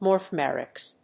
音節mor・phe・mics 発音記号・読み方
/mɔɚfíːmɪks(米国英語), mɔːfíːmɪks(英国英語)/